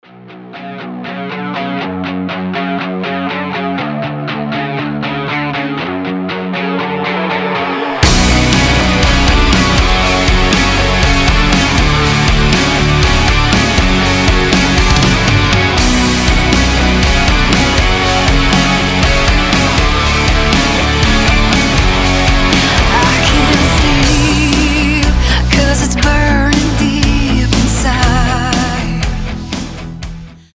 громкие
symphonic metal
gothic metal